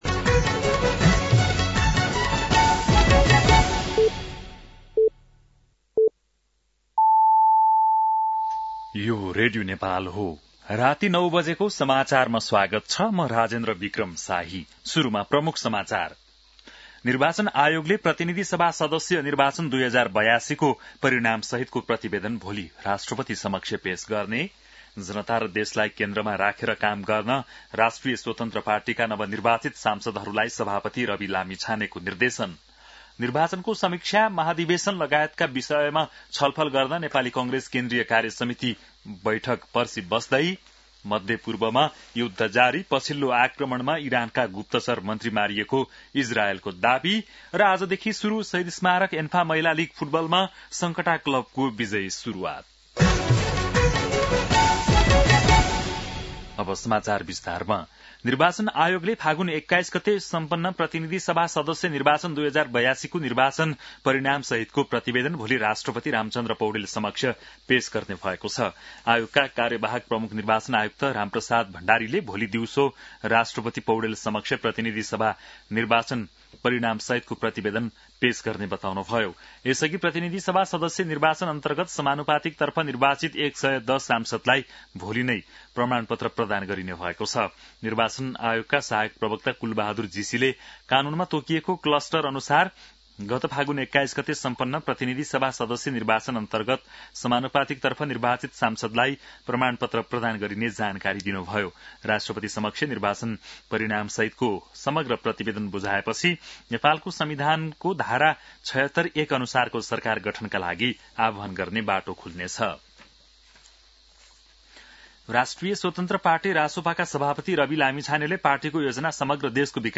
बेलुकी ९ बजेको नेपाली समाचार : ४ चैत , २०८२